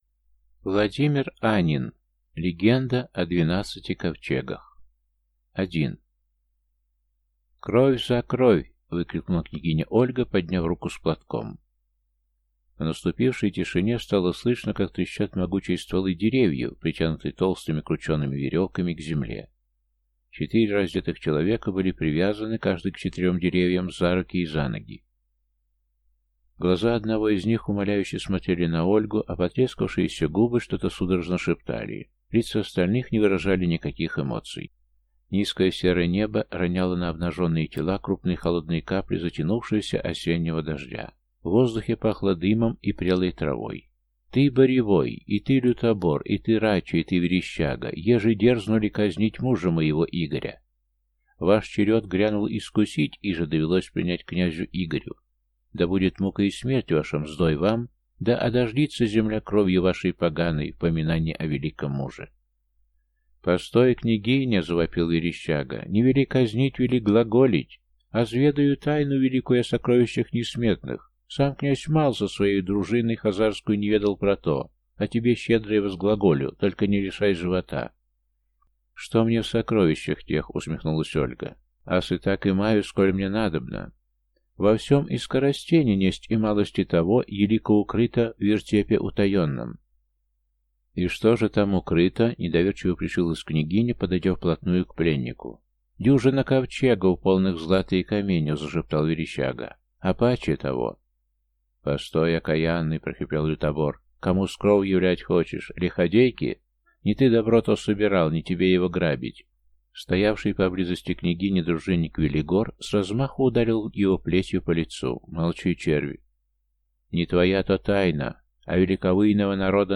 Аудиокнига Легенда о двенадцати ковчегах | Библиотека аудиокниг